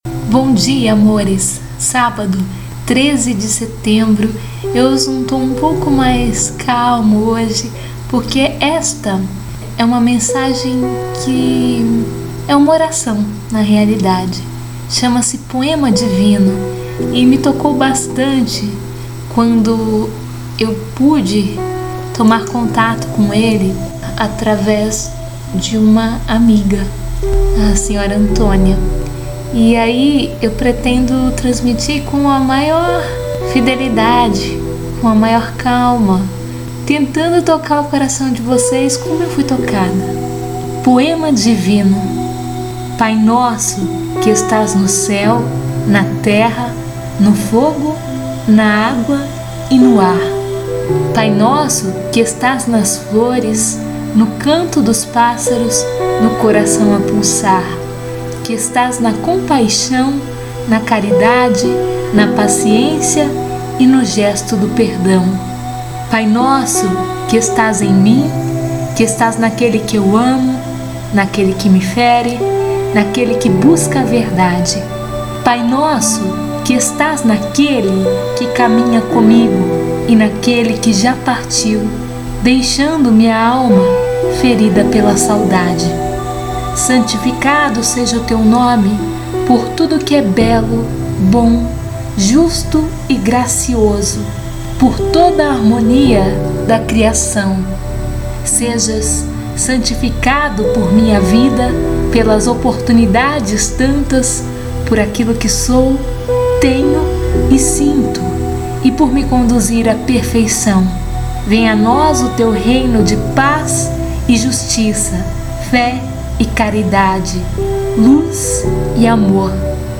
Um conjunto tanto pela beleza do poema quanto da forma que você narrou não derrubando o poema e acrescentando mais beleza!